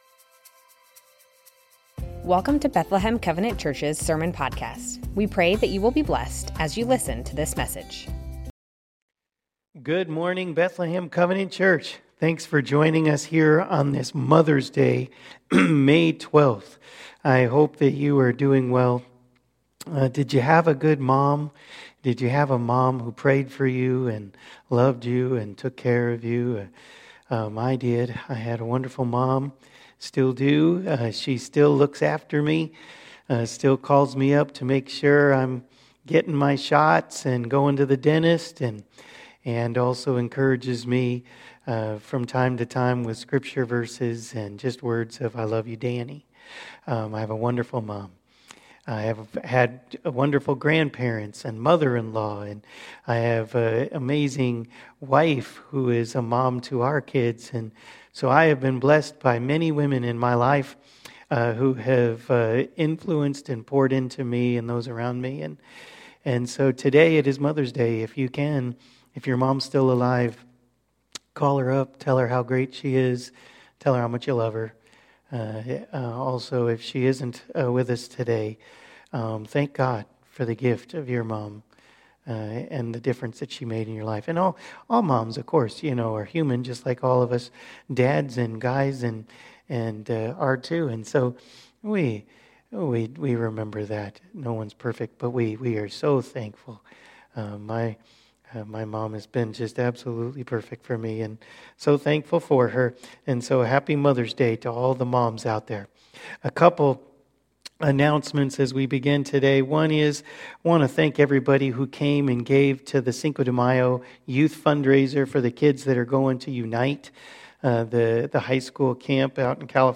Bethlehem Covenant Church Sermons James - Listening & Doing May 12 2024 | 00:30:09 1x 00:00 / 00:30:09 Subscribe Share Spotify RSS Feed Share Link Embed